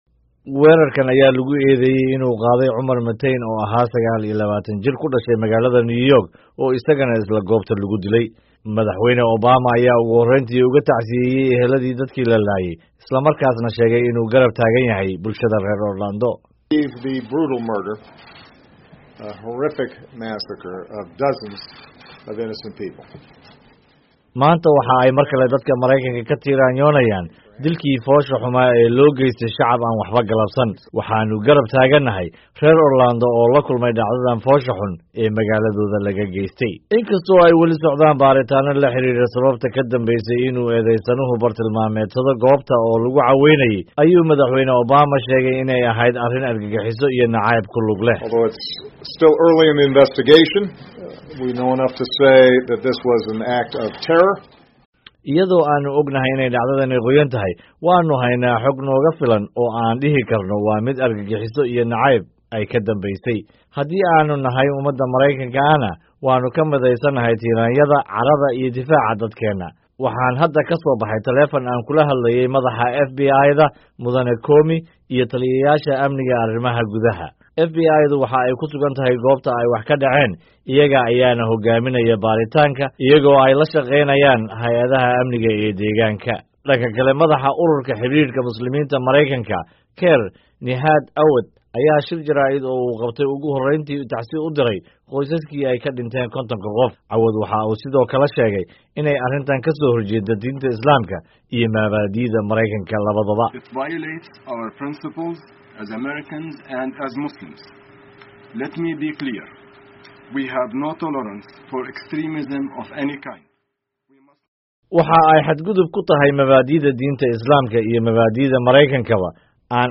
Dhageyso Obama oo Cambaareyay weerarka